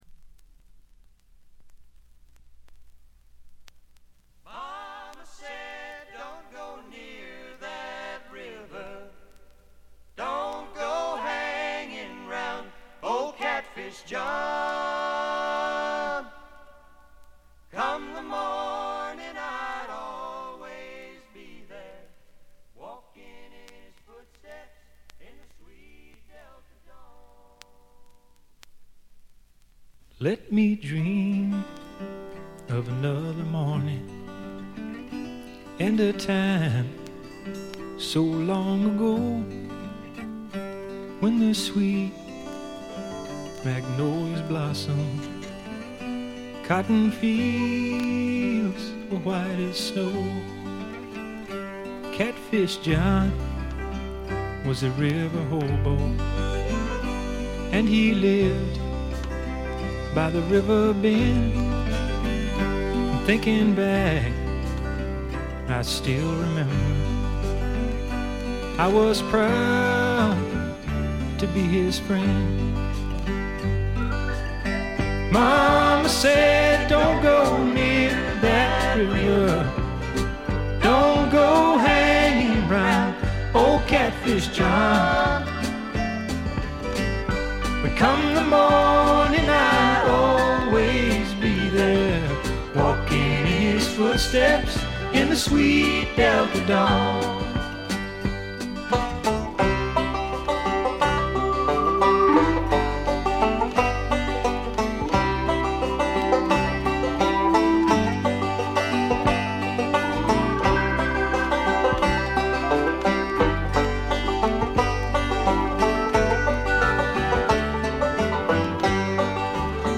アーシーなシンガー・ソングライターがお好きな方ならば文句なしでしょう。
試聴曲は現品からの取り込み音源です。